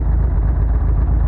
delorean_engine.ogg